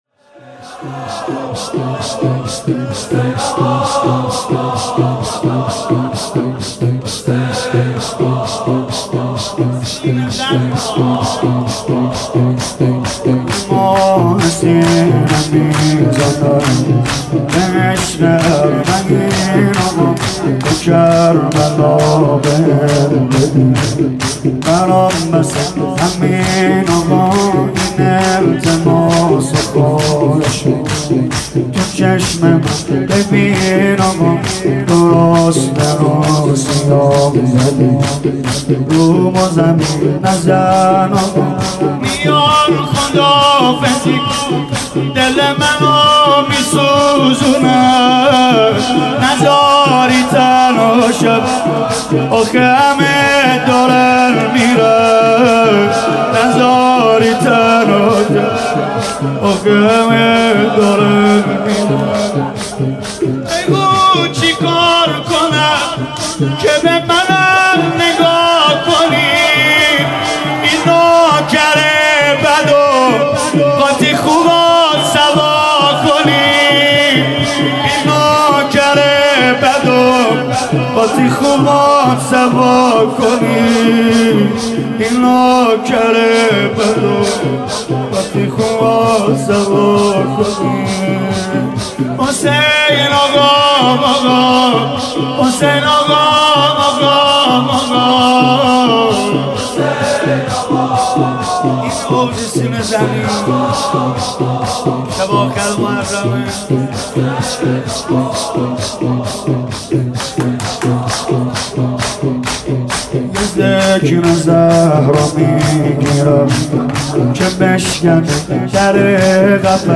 «مداحی ویژه اربعین 1396» شور: به عشق اربعین آقا